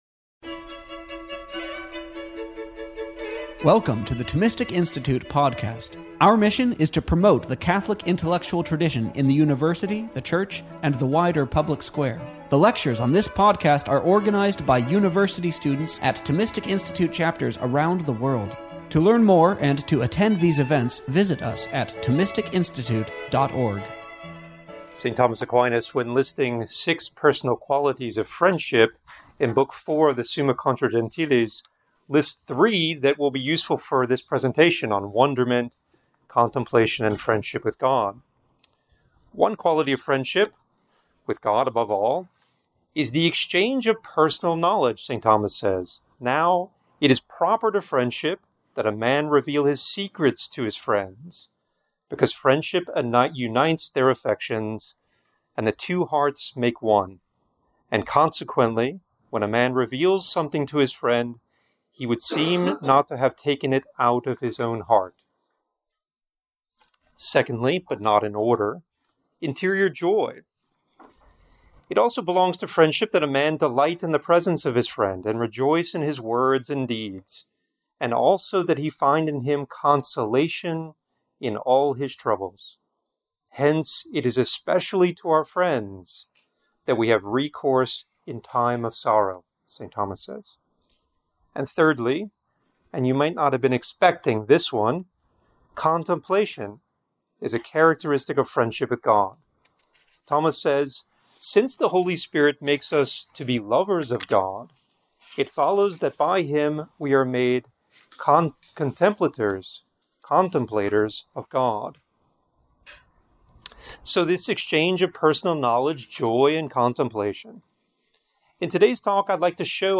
This lecture was given on January 18th, 2025, at Cedarbrake Catholic Retreat Center.